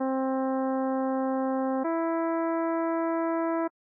c1e1.ogg